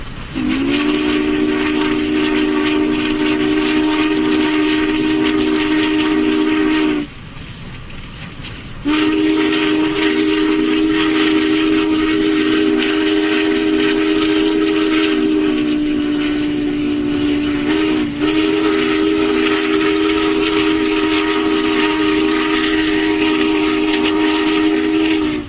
Genres: Misc (59) - Train (6) - Whistle (16)